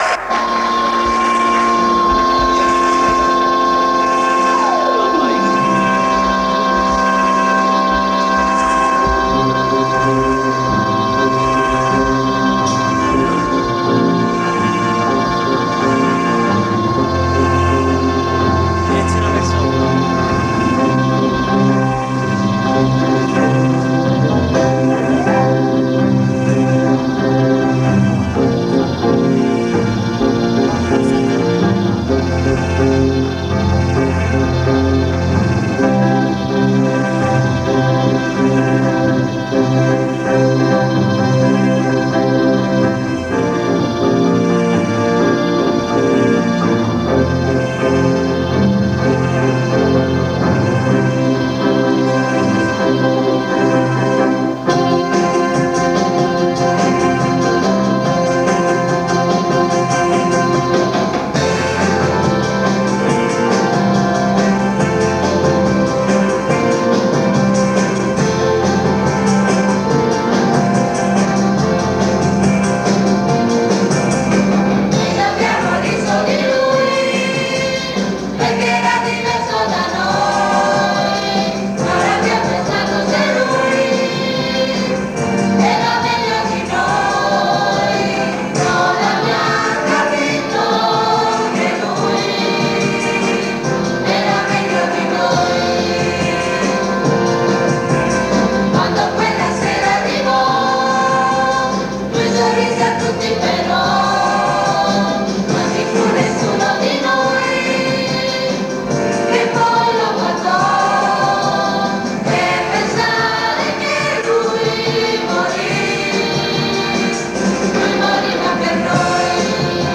Dopo un anno di prove, cambiamenti e sistemazioni, finalmente il recital andò in scena, al Cinema Teatro Nuovo (ora demolito) di Polignano a Mare, e fu molto apprezzato.